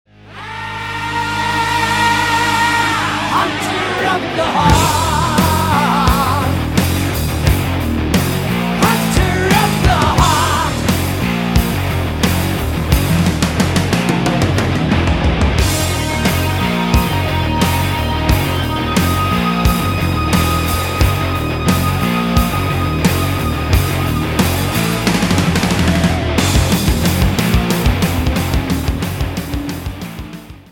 электрогитара
heavy Metal